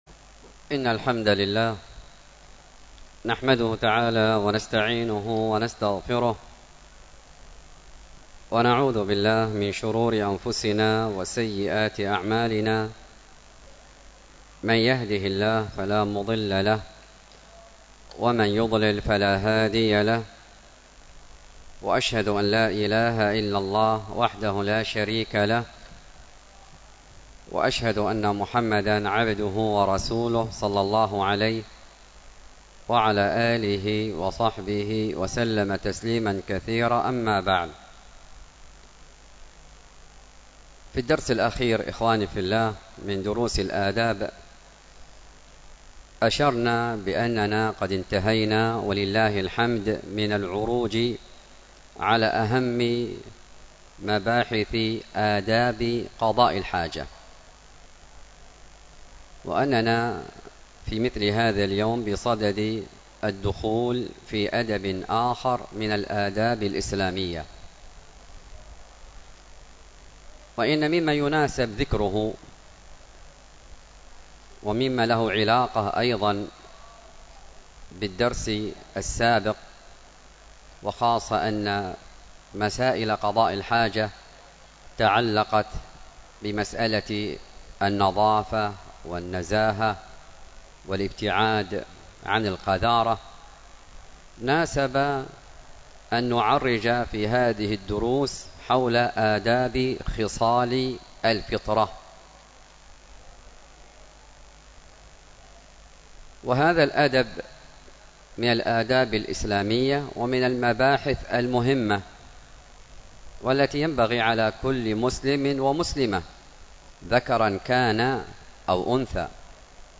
الدرس في آداب خصال الفطرة 2، الختان حكمه وحكمته ومشروعيته ومسائله .